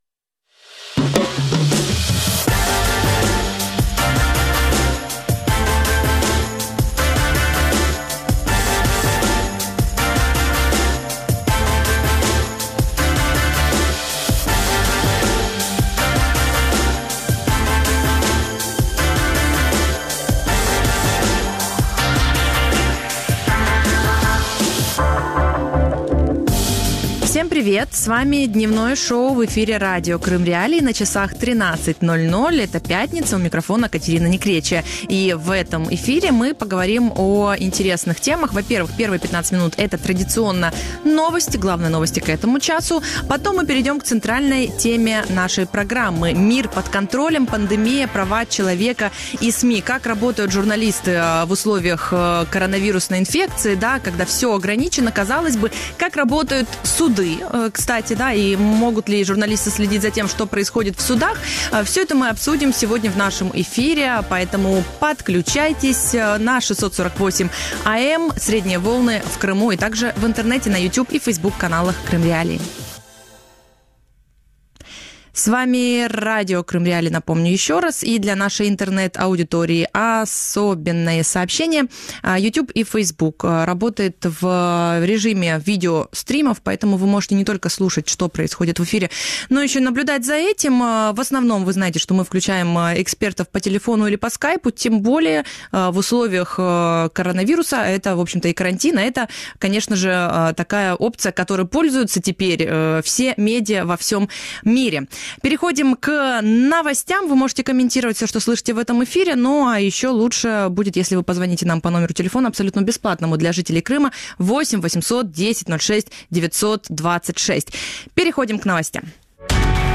Мир под контролем. Пандемия, права человека и СМИ | Дневное ток-шоу